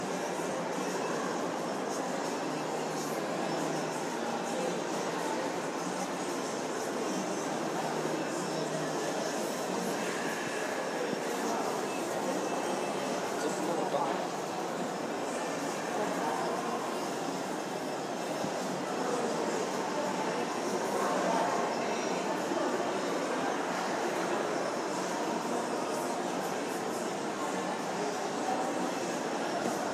Field Recording
Roosevelt Field Mall (Upper Level)  4/27/16  5:58pm
Sounds: Many people talking (their voices bounce around in the large open space creating a very mumbled sound), two guys walk by talking (they are heard clearer than the rest of the crowd), and music can be heard from the near by Charlotte Russe.